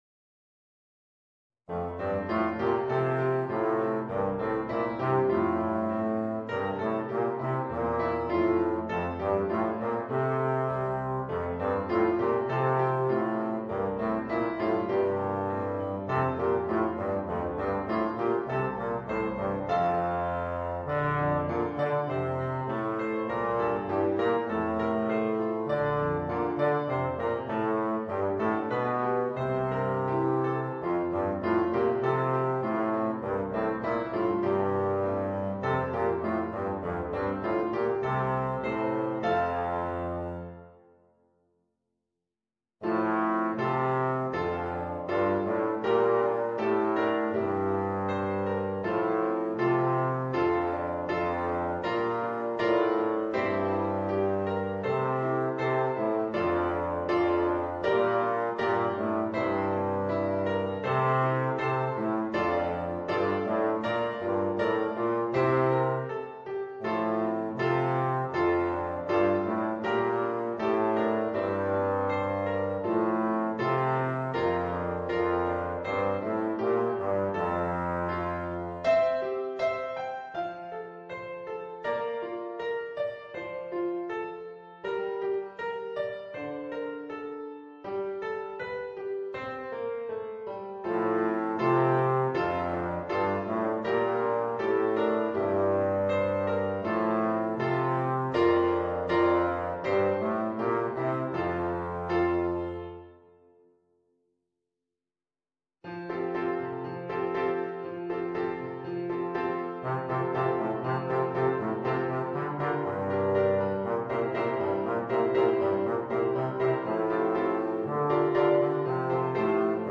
Voicing: Bass Trombone and Piano